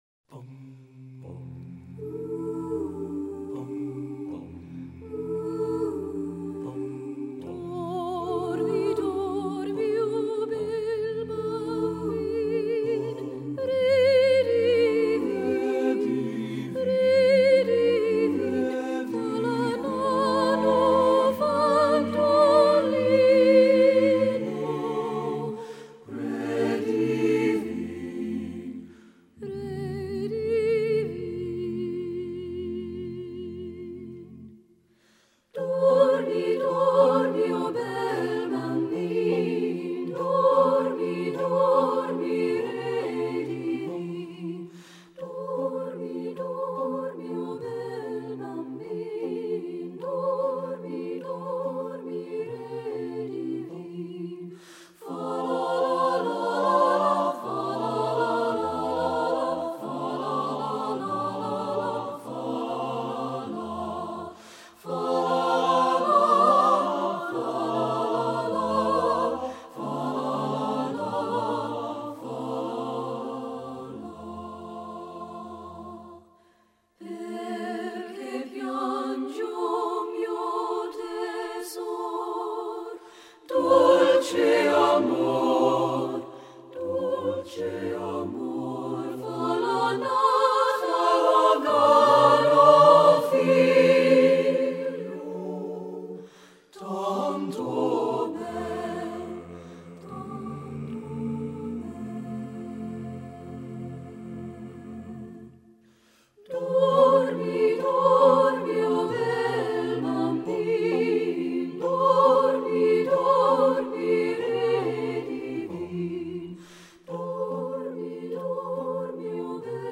Composer: Italian Christmas Ca
Voicing: SATB